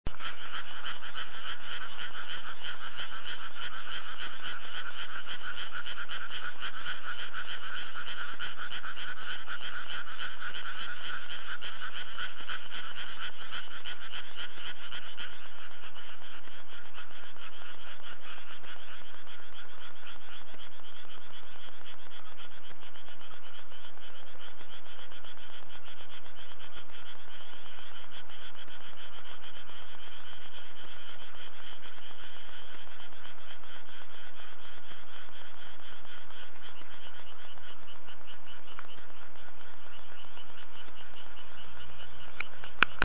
カエルの合唱
数年前に転居してからは田んぼとは無縁だったんですが、今の住処はどうかなと思っていたら…畑ばかりの中に数枚田んぼがあったようで、夜になると幸いにも彼らの合唱を聴くことができる環境になりました。
ちょっと音が小さめですが、よろしければどうぞ。